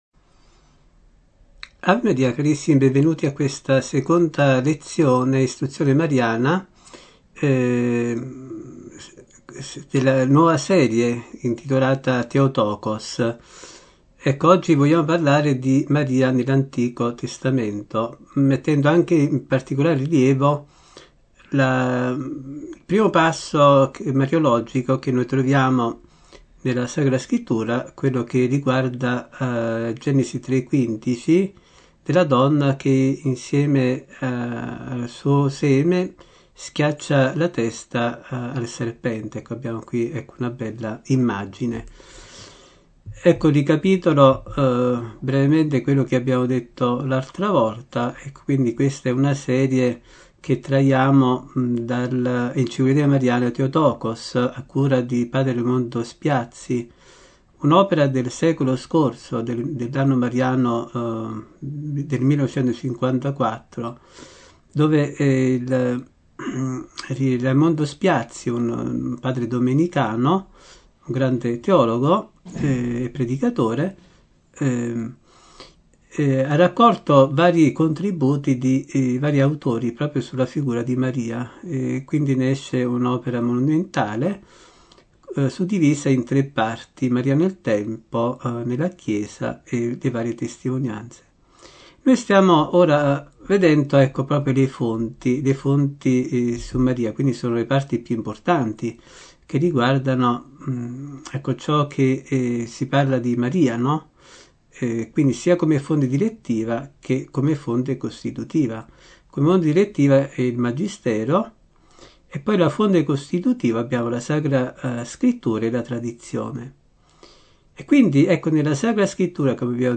Genere: Catechesi domenicali.